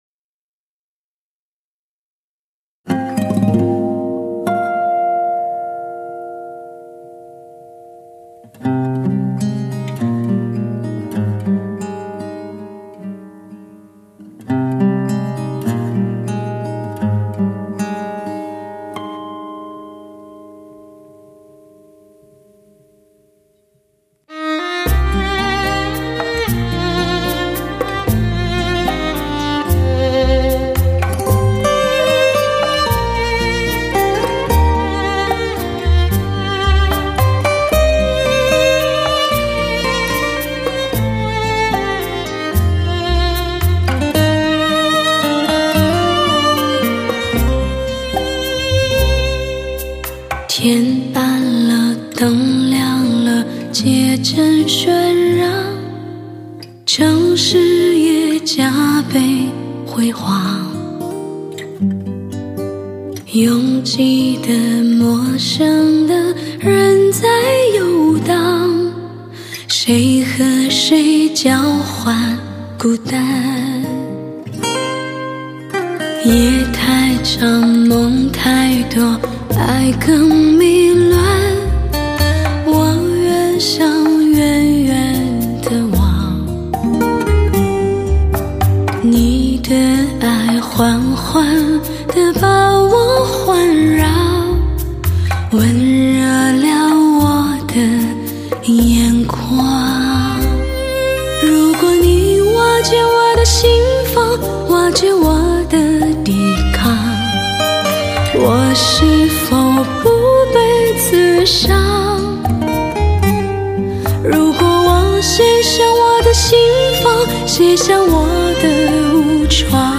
有品味的聆听，享受和你一起吹风的浪漫舒缓的旋律，悠闲的空间，乘风的浪漫，无比的音响效果……